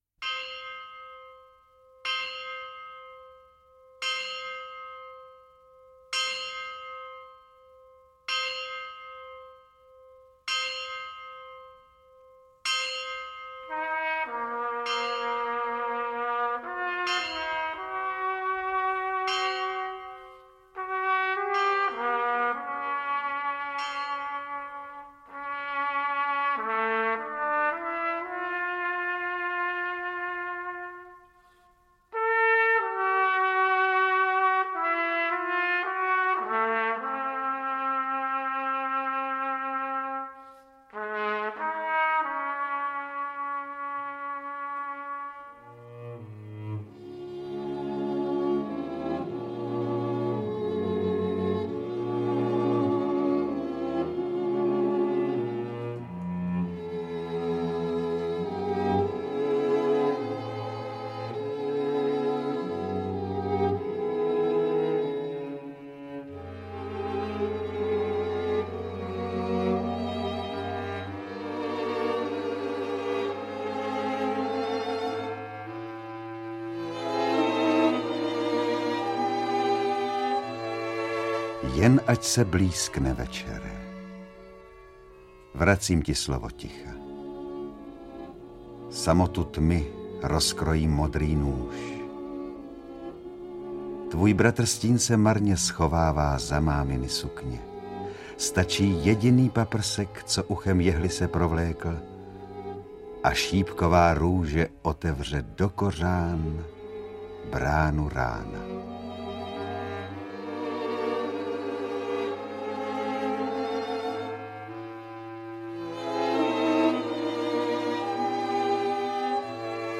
Audiobook
Read: Alfred Strejček